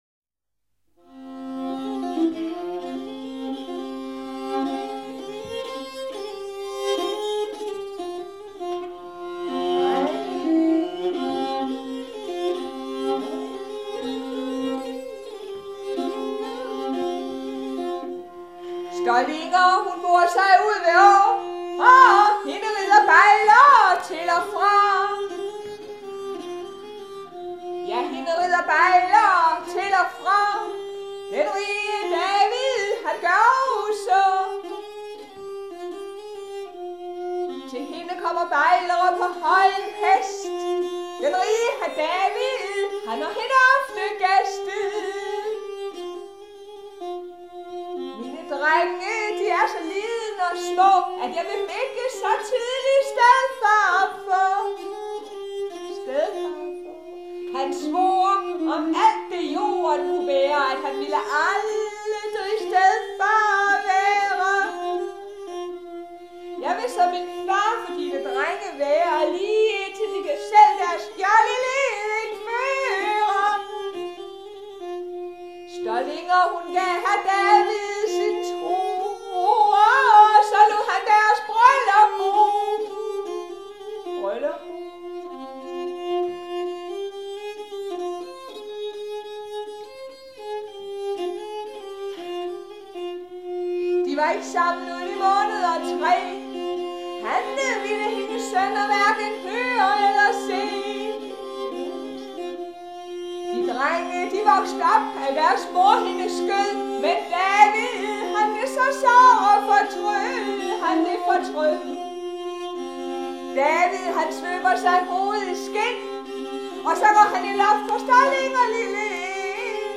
Talesang 1.
Traditionel dansk balladesang, -musik og -dans.